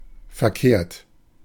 Ääntäminen
Synonyymit illusoire factice erroné antirationnel Ääntäminen France: IPA: [fo] Haettu sana löytyi näillä lähdekielillä: ranska Käännös Ääninäyte Adjektiivit 1. unrichtig Substantiivit 2.